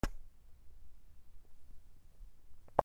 おなら
/ J｜フォーリー(布ずれ・動作) / J-25 ｜おなら・大便